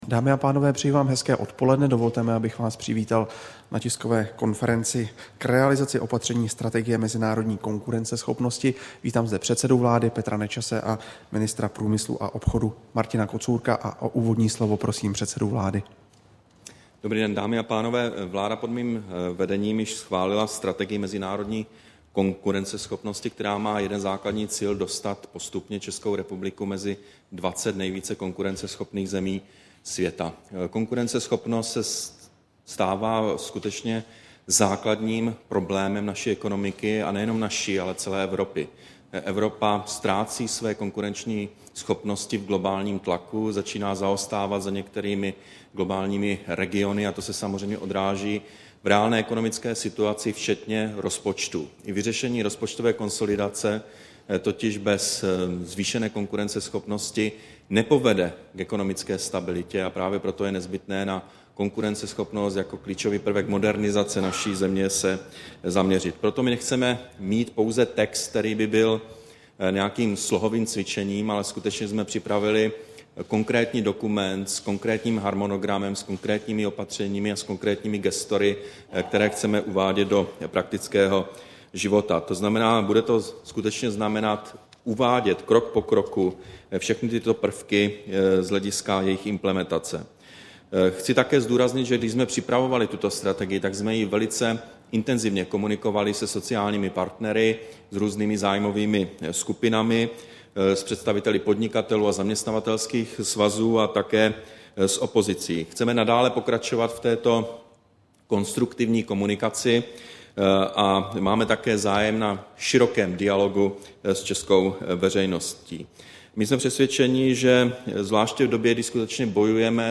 Tisková konference k realizaci opatření Strategie mezinárodní konkurenceschopnosti, 20. října 2011